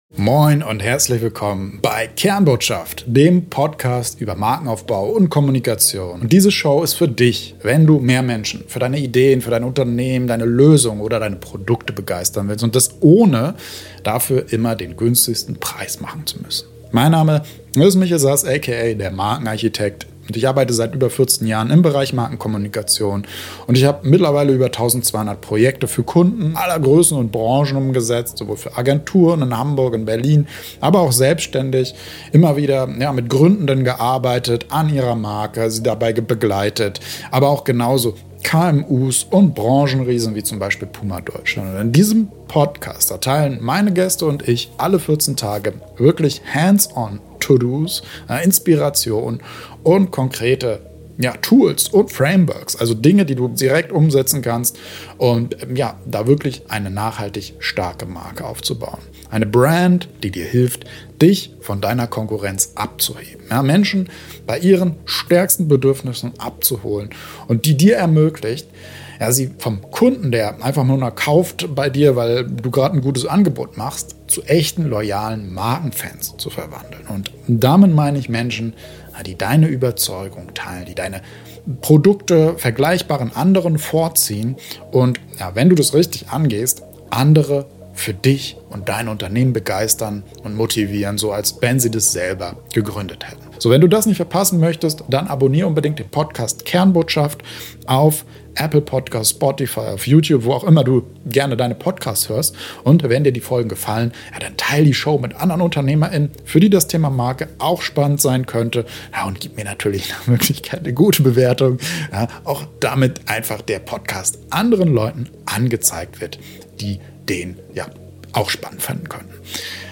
Intro & Outro Musik